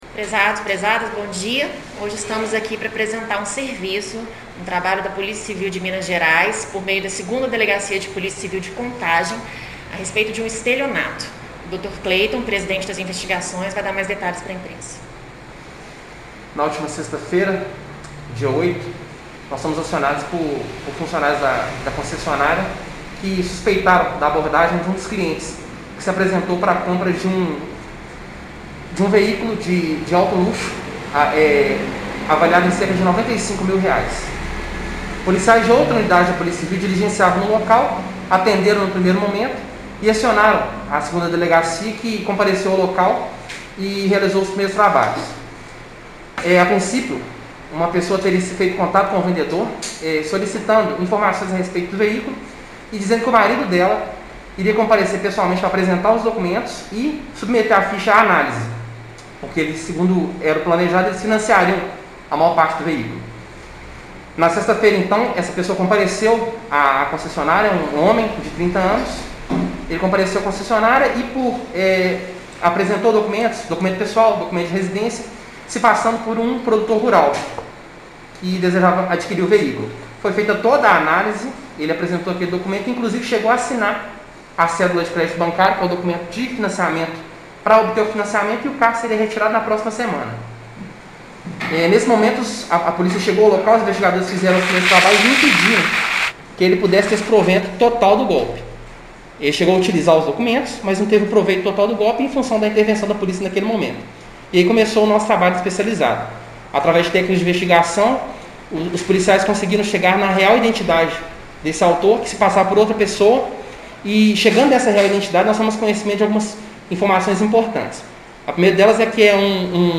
Sonora da coletiva
Coletiva-Estelionato.mp3